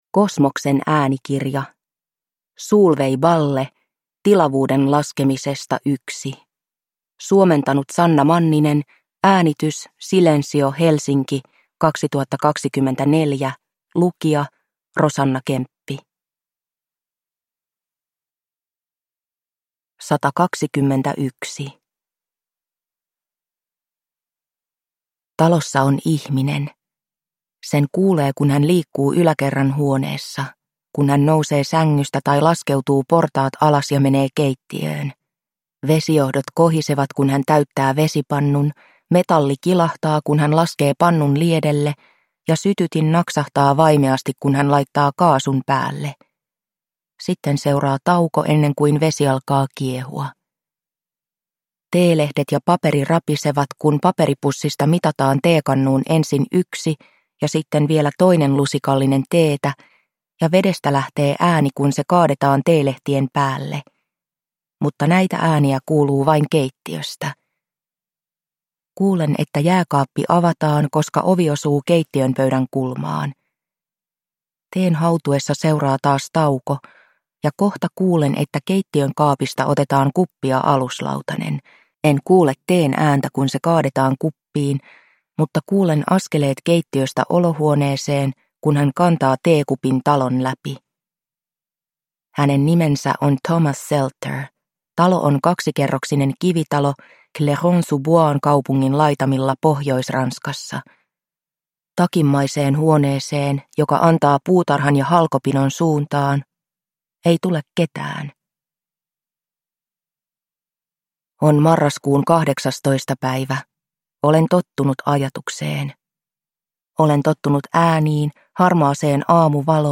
Tilavuuden laskemisesta I – Ljudbok